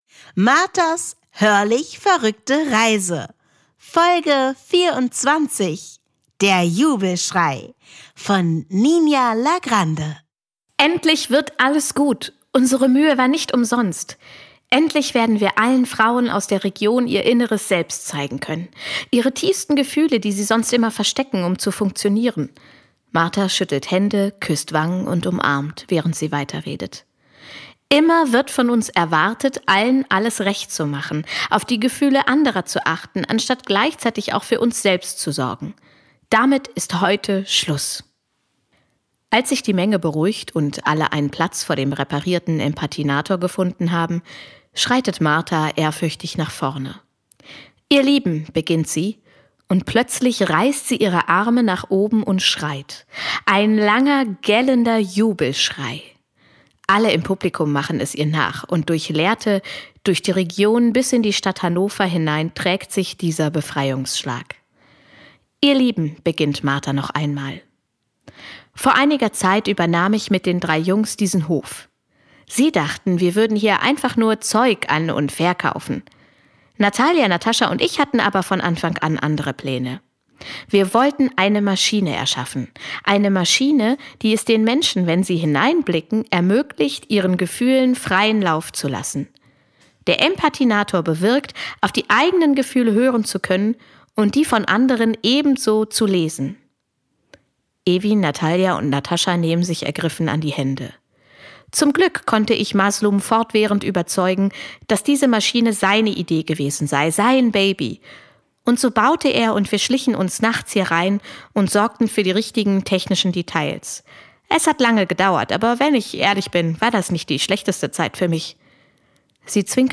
Eine Fortsetzungsgeschichte in 24 Kapiteln
Einzige Bedingung: ein vorgegebenes lautmalerisches Wort einzubauen – vom Glöckchenklingeln über Affengebrüll bis zum Schalldämpfer.
Schauspielerin und Musikerin Denise M’Baye umrahmt die Kapitel als Gastgeberin.